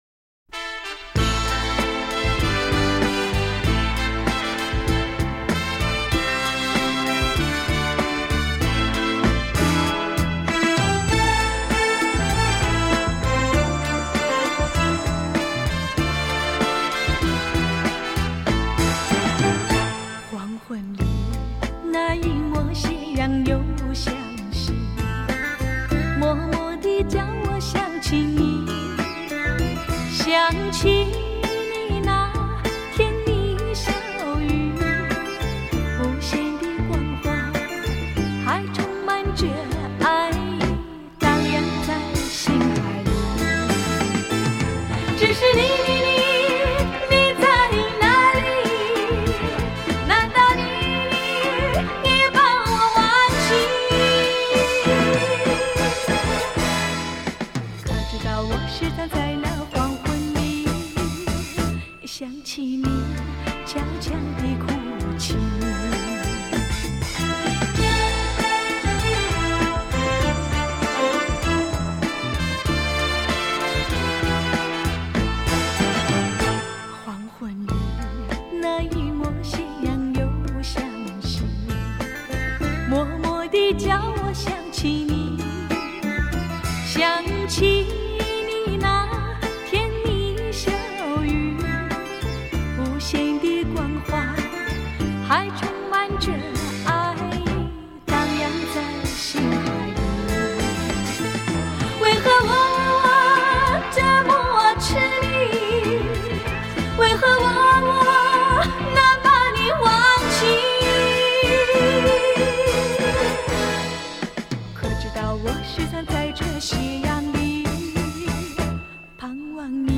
全部由原裝母带重新录制 令重播效果更臻完美